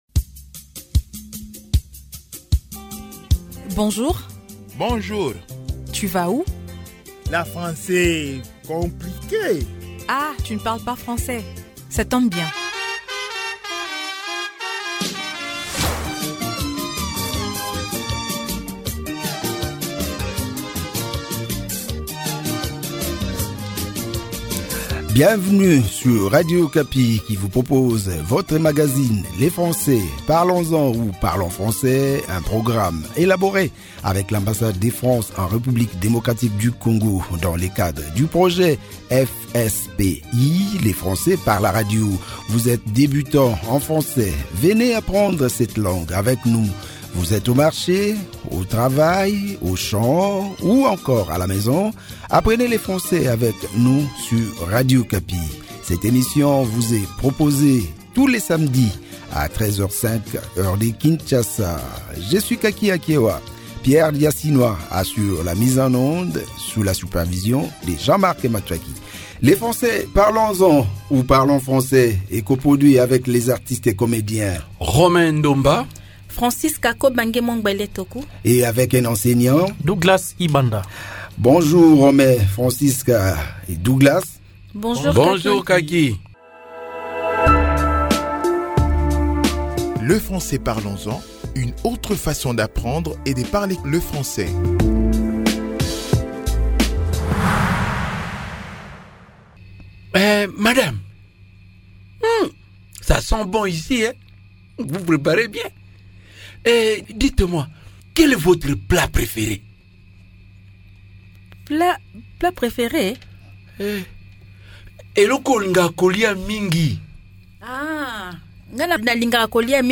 Les animateurs de l'emission Parlons Français échangent sur leurs repas préférés